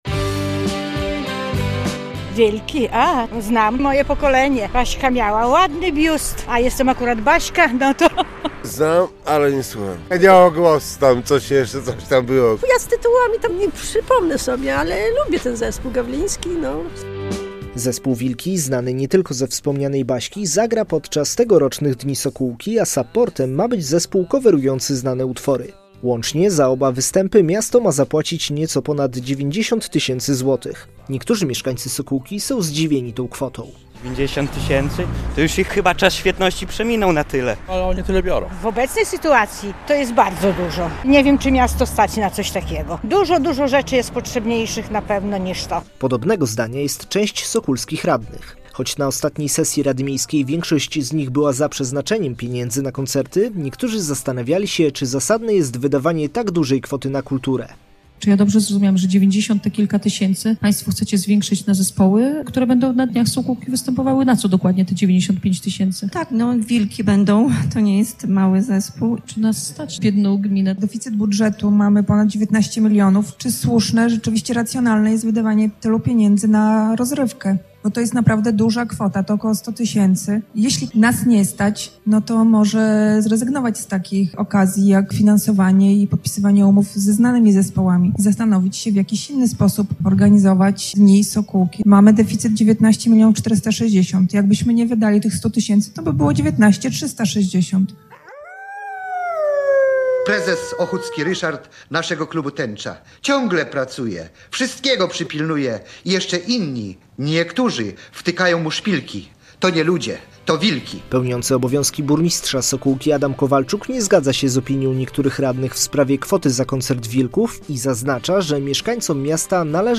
Kontrowersje wokół wydatków na koncert Wilków w Sokółce - relacja